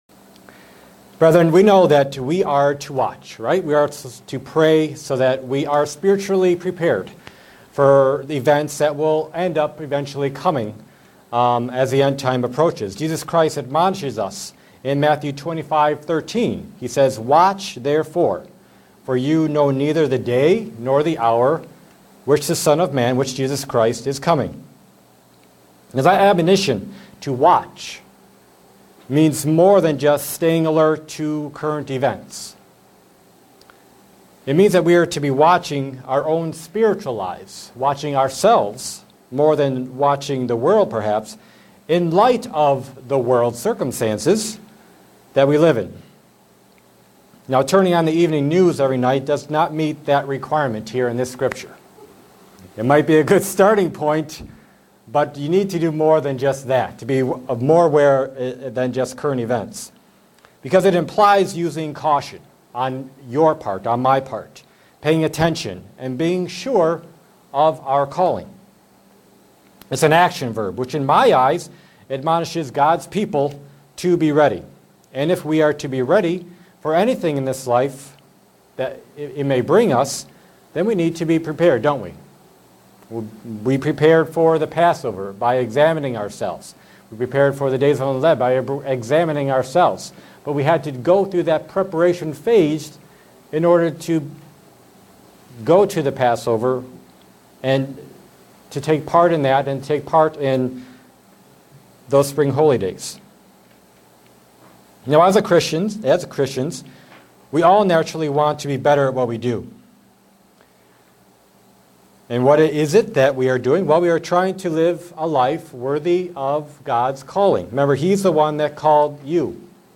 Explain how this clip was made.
Given in Buffalo, NY